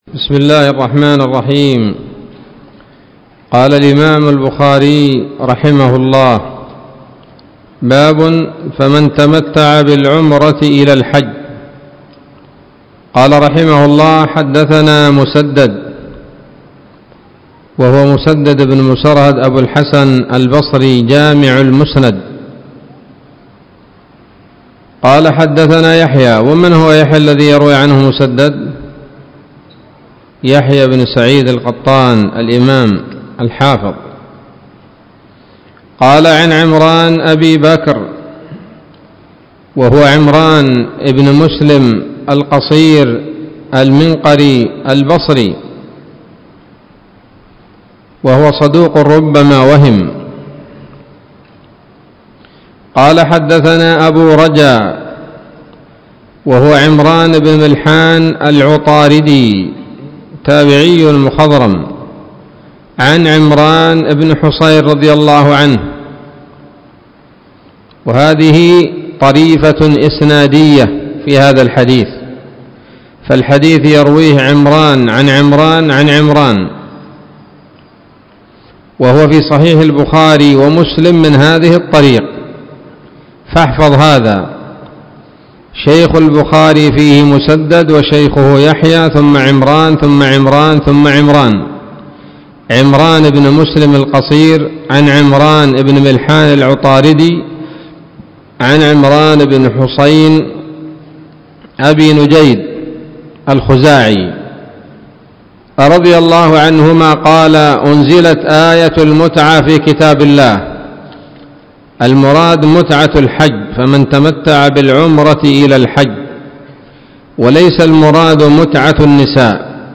الدرس الثامن والعشرون من كتاب التفسير من صحيح الإمام البخاري